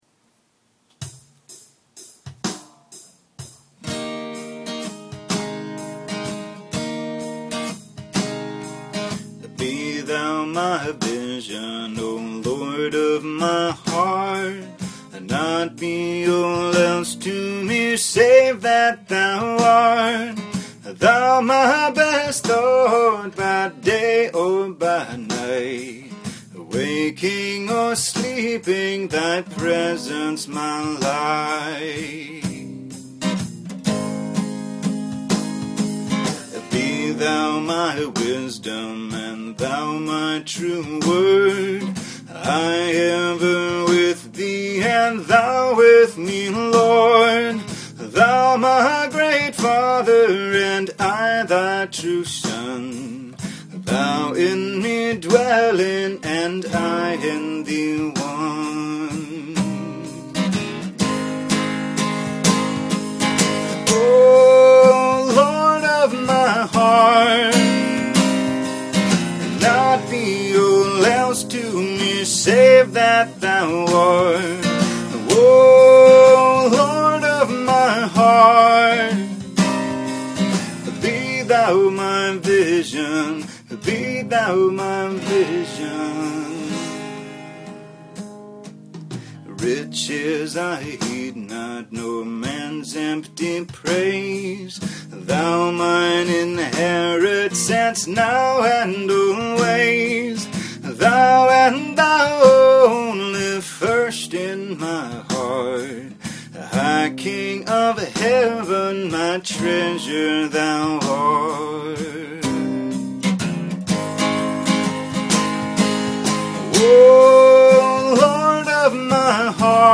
Irish folk melody